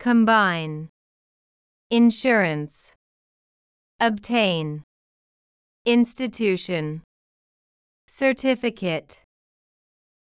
音声を再生し、強勢のある母音（＝大きな赤文字）を意識しながら次の手順で練習しましょう。